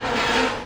jammed.wav